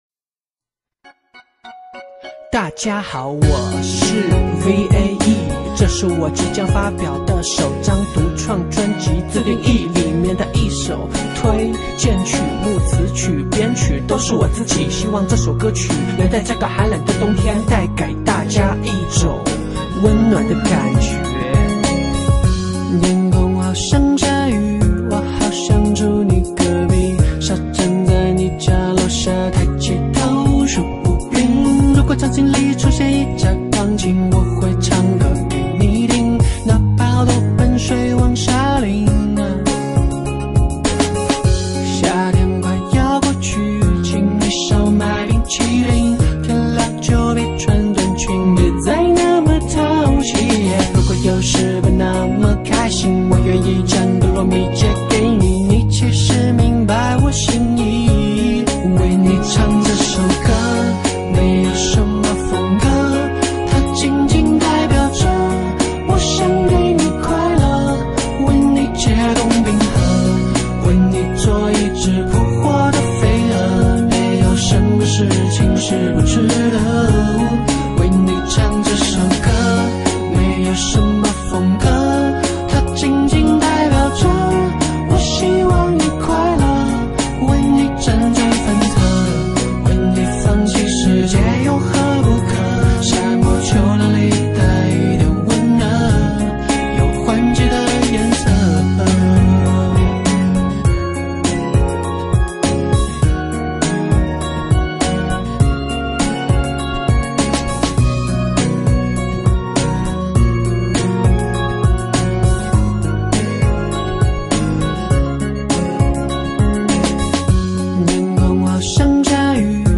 旋律线跳跃明快。